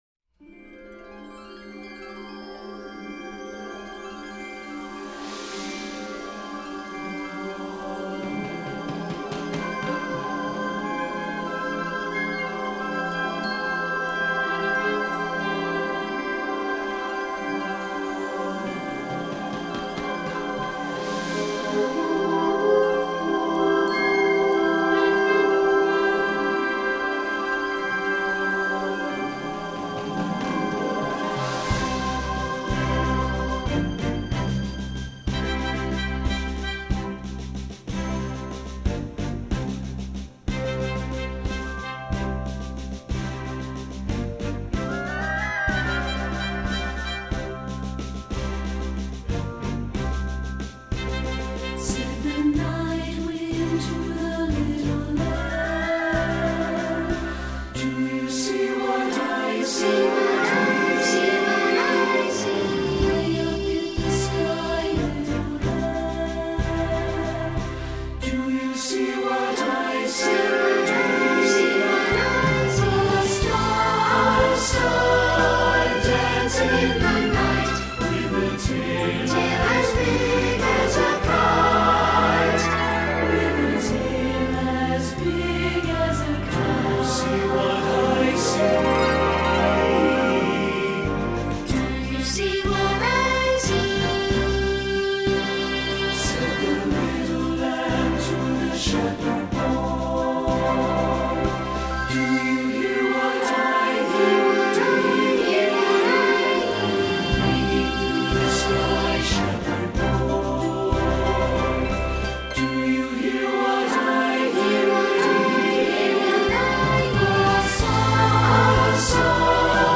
Voicing: SATB and Children's Choir